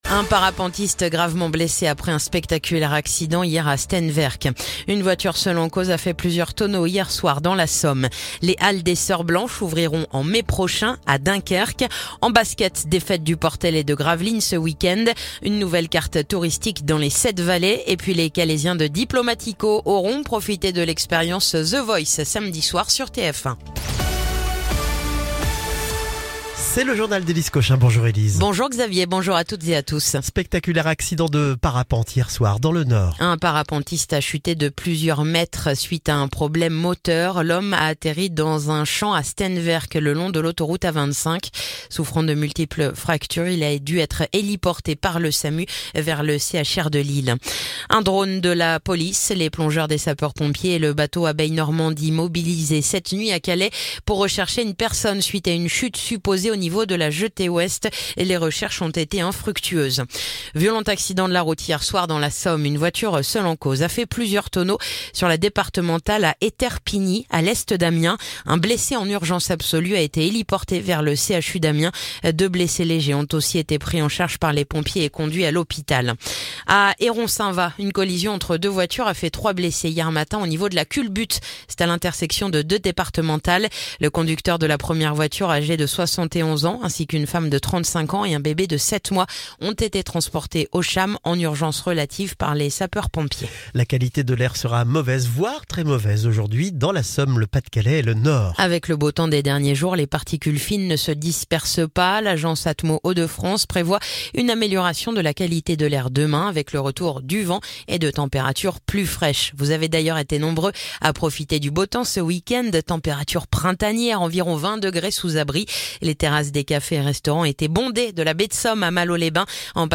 Le journal du lundi 10 mars